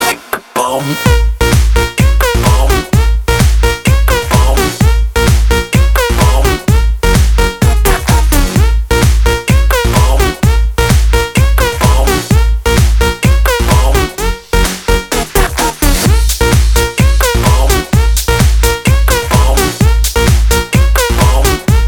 Стиль: Electro House / Electro